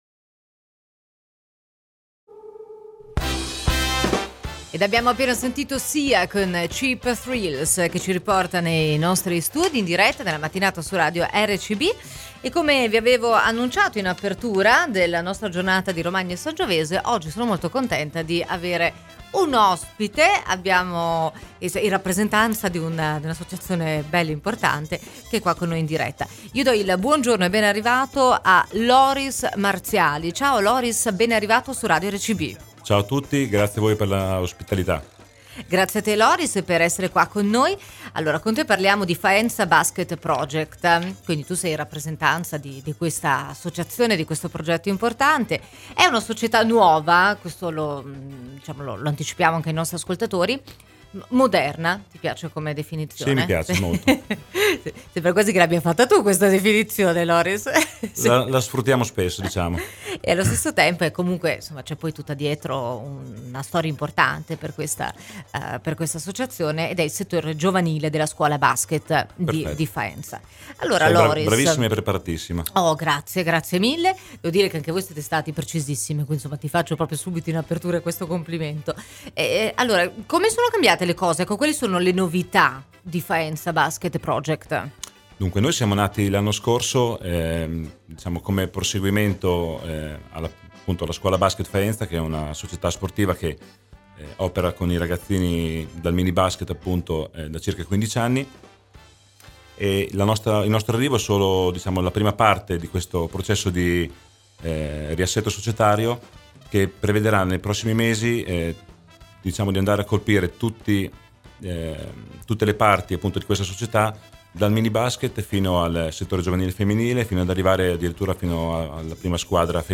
Oggi siamo stati ospiti di Radio RCB, durante la diretta del mattino.
intervista-basket.mp3